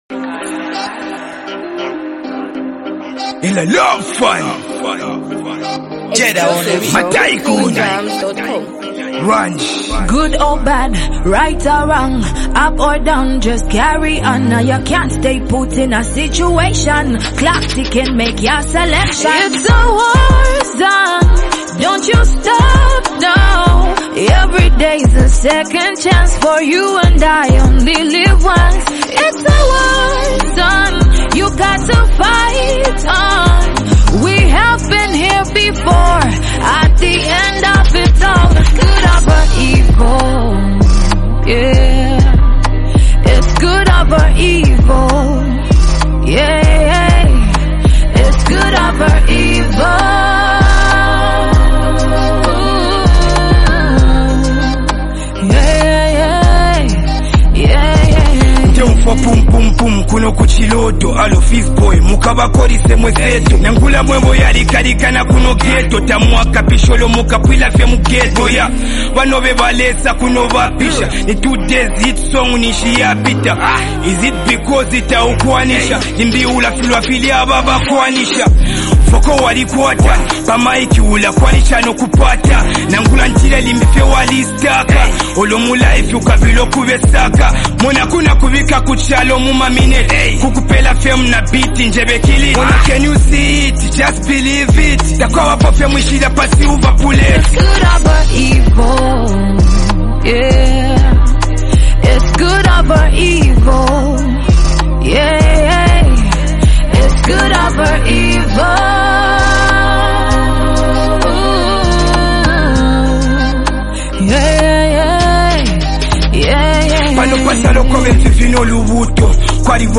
an uplifting and motivational track
blends inspirational tones with a modern Zambian sound